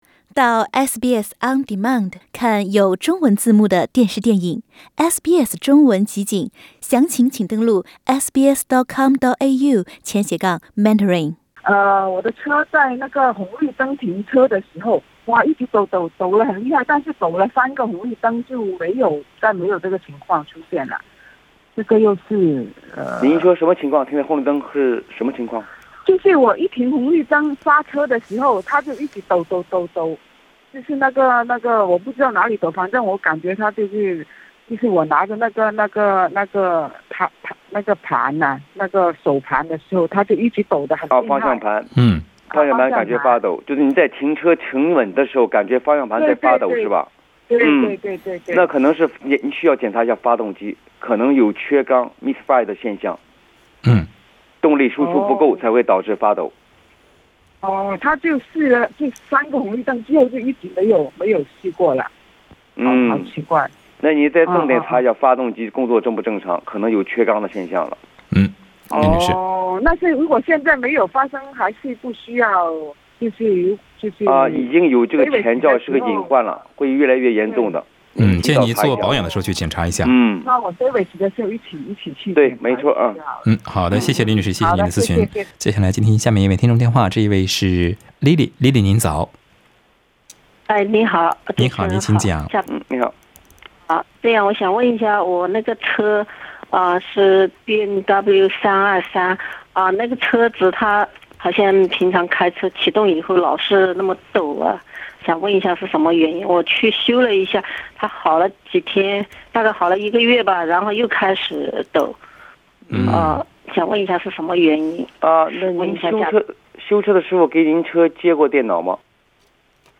在本期《空中汽車診所》聽眾熱線節目中，有聽眾在節目中諮詢汽車在等候交通燈時方向盤髮抖的原因。